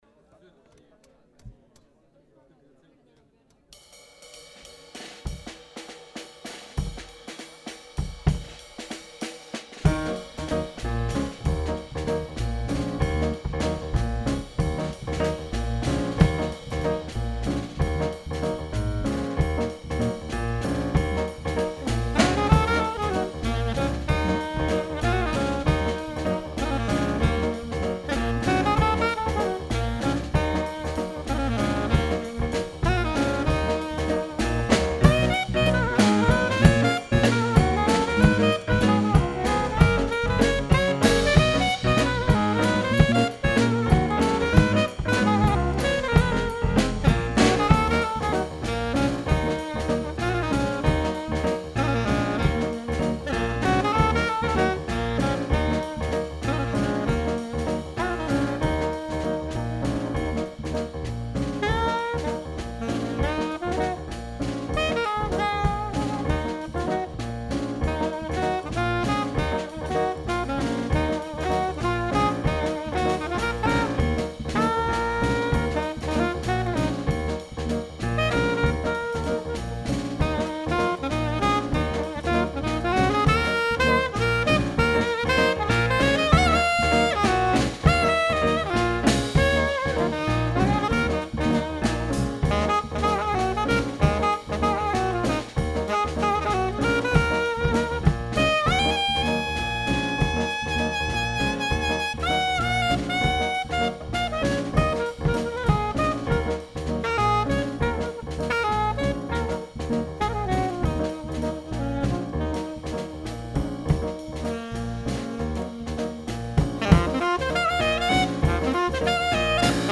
Naskytla se nám možnost zahrát si ve venkovních prostorech kavárny Podnebí.